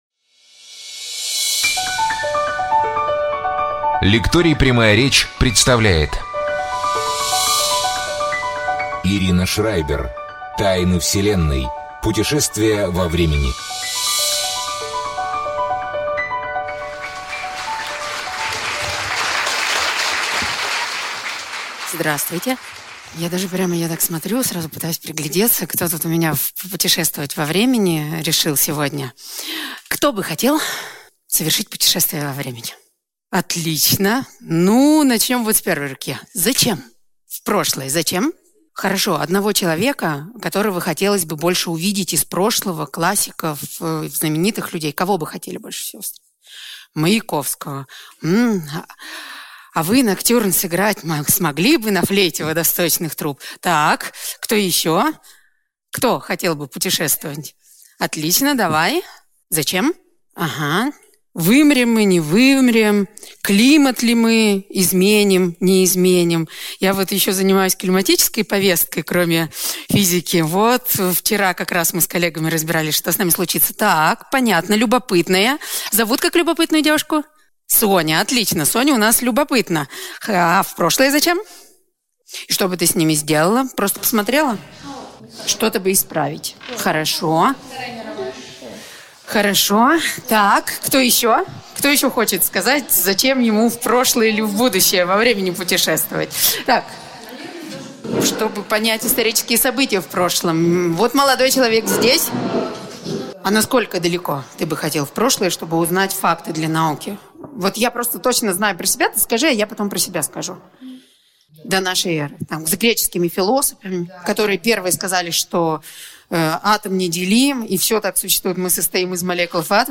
Лекция «Тайны Вселенной. Путешествия во времени»